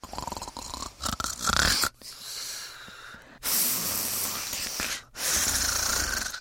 Звуки женского храпа
Звук громкого храпа юной девушки